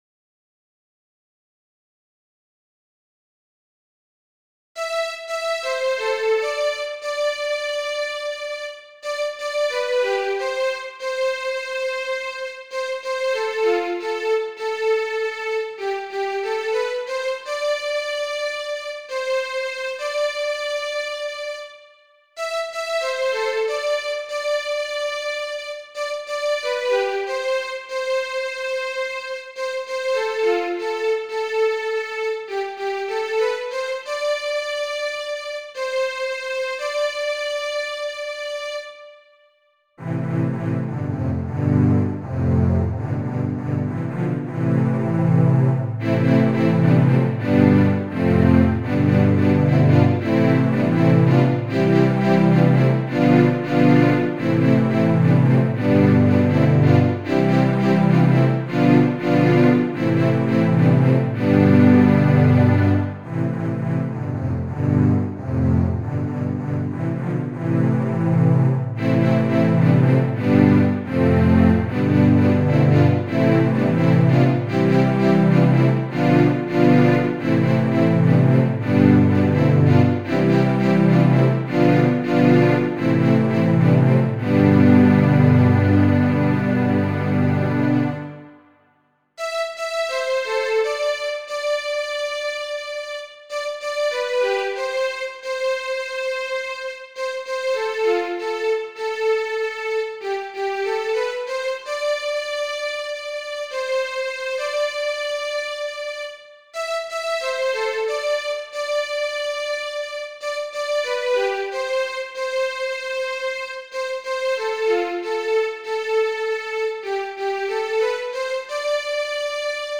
Classic